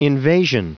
Prononciation du mot invasion en anglais (fichier audio)
Prononciation du mot : invasion